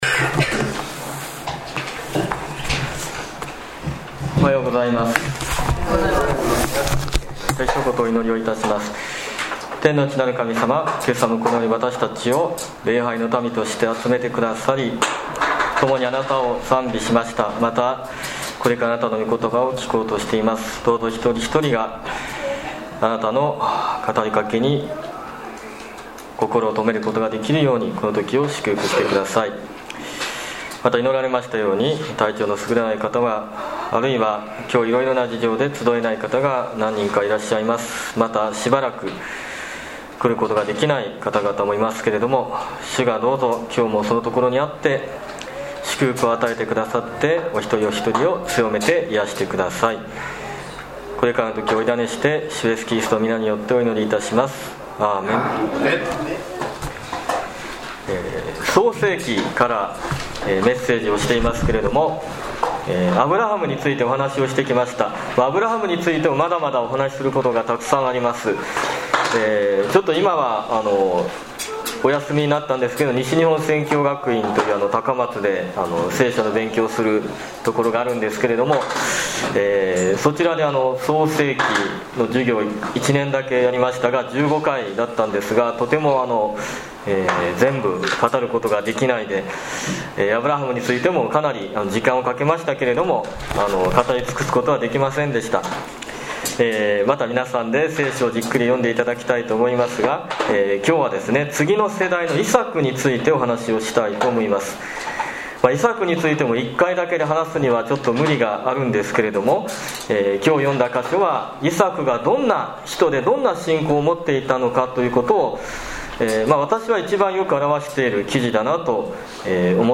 2018年2月25日礼拝メッセージ